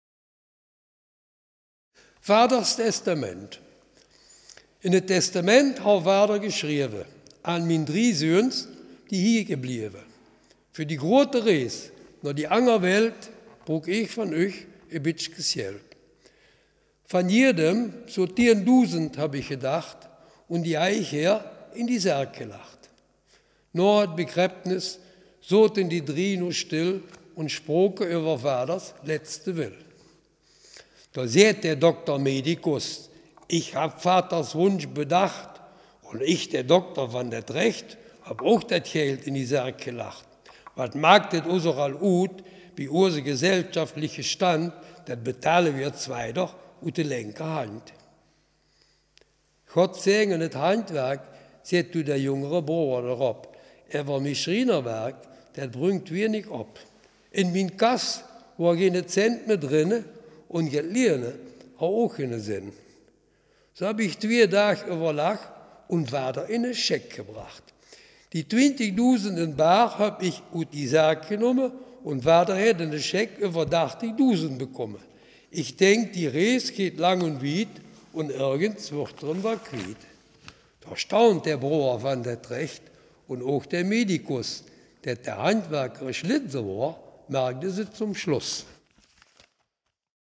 Selfkant-Platt
Geschichte